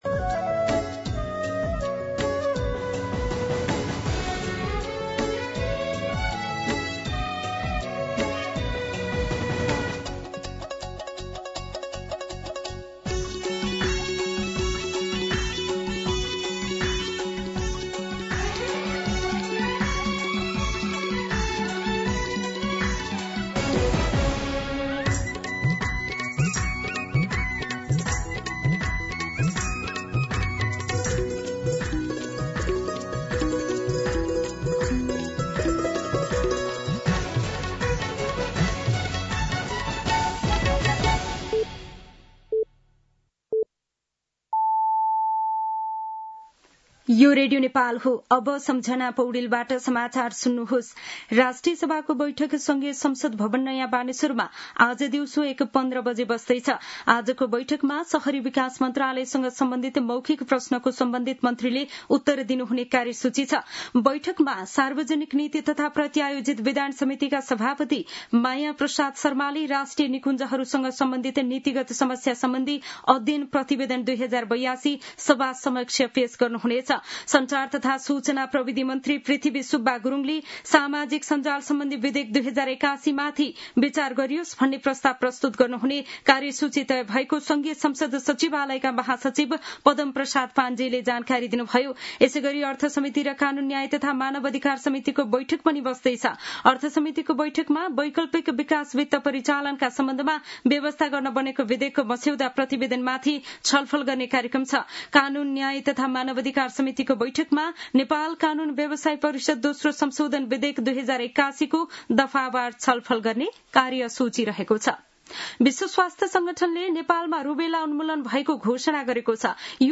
मध्यान्ह १२ बजेको नेपाली समाचार : ३ भदौ , २०८२